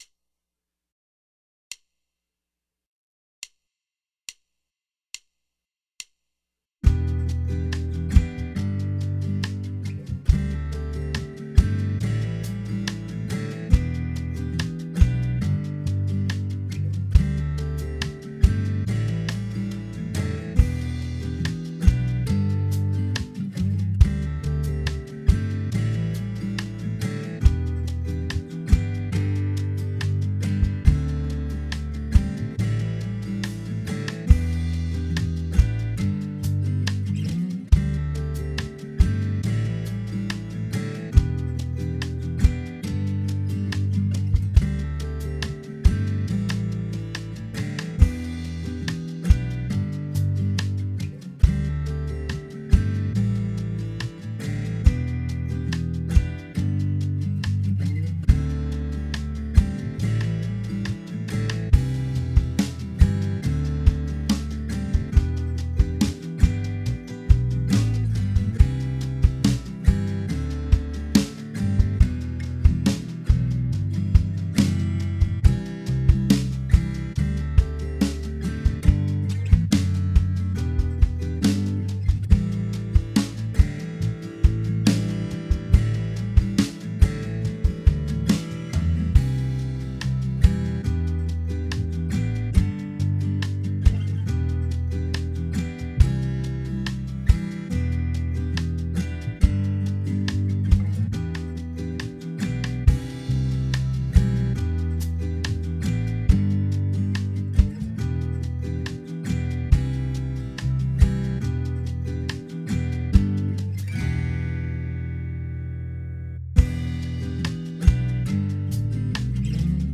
Key: Em
Tempo: 70